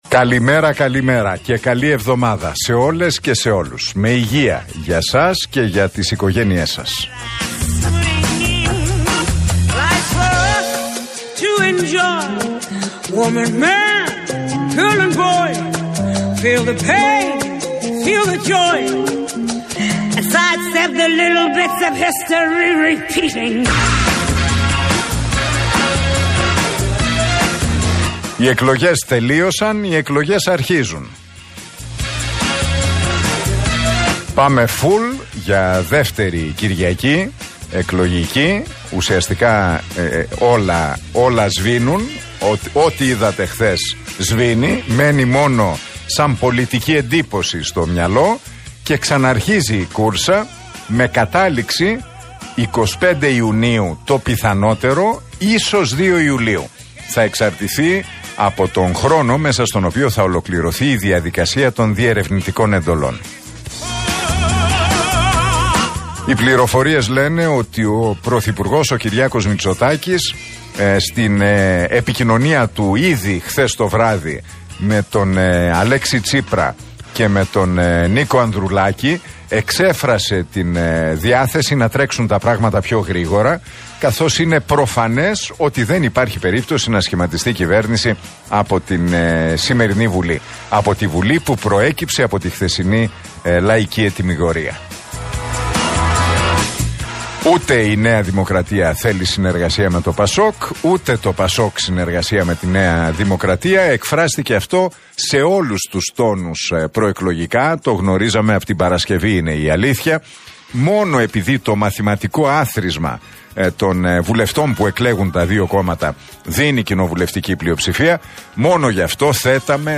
Ακούστε το σχόλιο του Νίκου Χατζηνικολάου στον RealFm 97,8, την Δευτέρα 22 Μαΐου 2023.